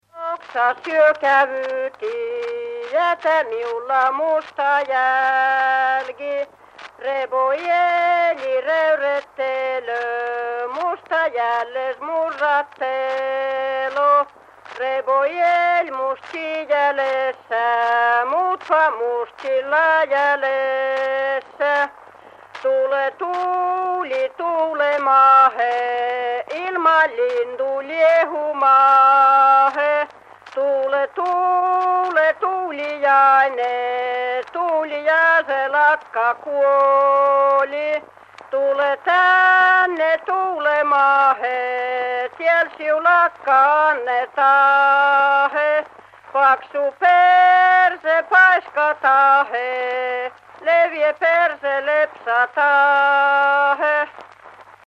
Tuulta on voitu nostattaa huutamalla, laulamalla, rukoilemalla, huhuilemalla, loitsimalla, joikumalla – tapoja on ollut monia. Tärkeänä osana nostossa on ollut vihellys tai koirankutsumainen huudahdus.